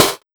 BIG125SDHH-L.wav